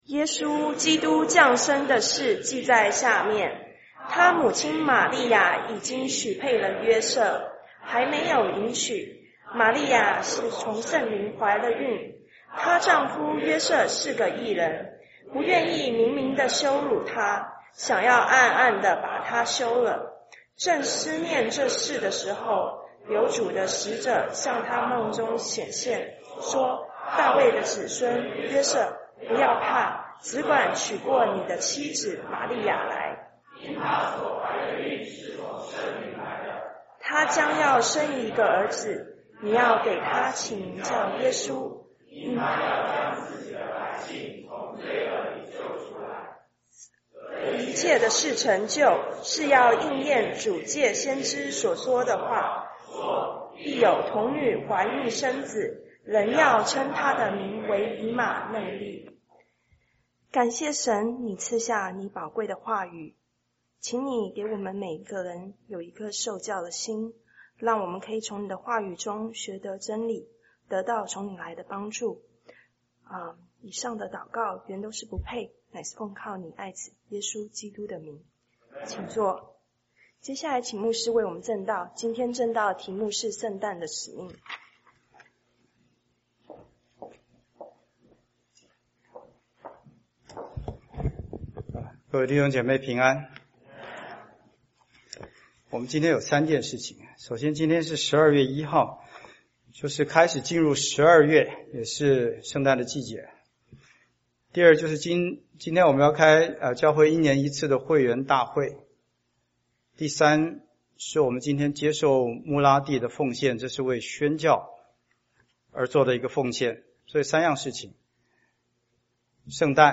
2013 主日證道 | Series | Chinese Baptist Church of West Los Angeles